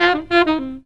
COOL SAX 1.wav